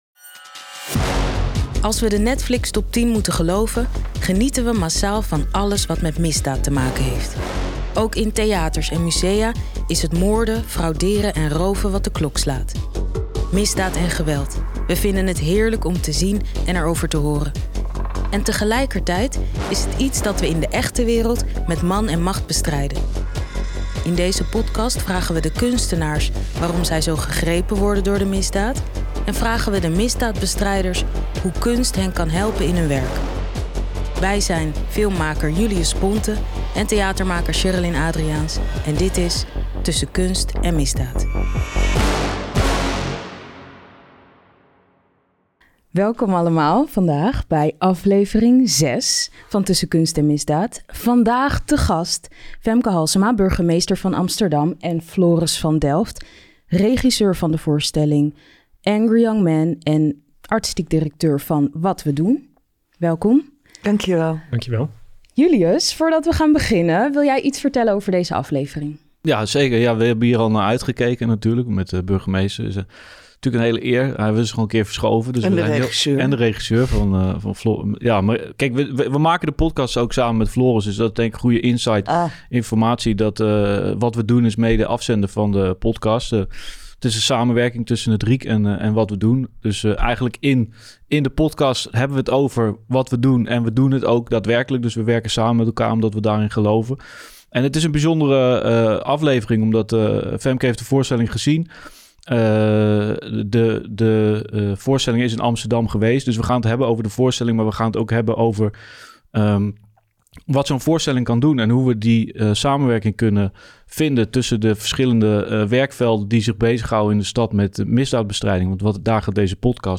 In de 6-delige podcastserie Tussen Kunst en Misdaad gaan misdaadbestrijders het gesprek aan met kunstenaars en makers over hoe een voorstelling, film of kunstwerk mee kan helpen een brug te slaan tussen de burger en de overheid.